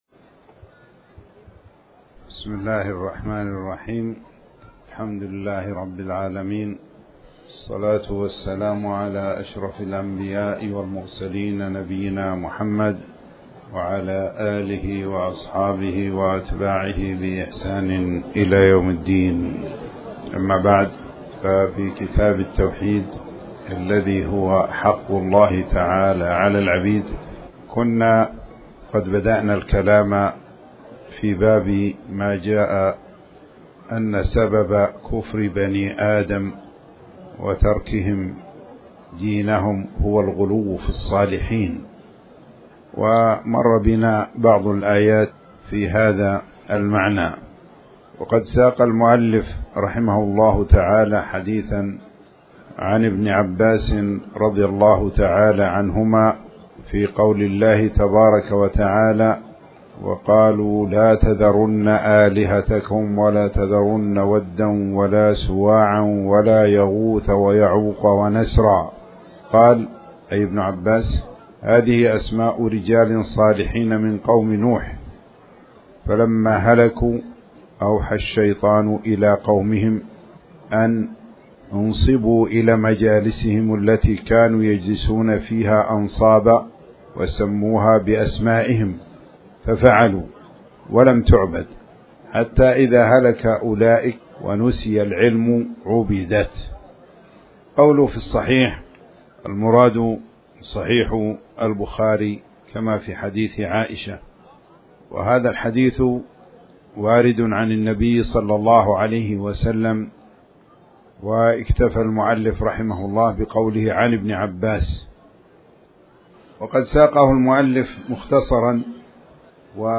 تاريخ النشر ١٣ محرم ١٤٤٠ هـ المكان: المسجد الحرام الشيخ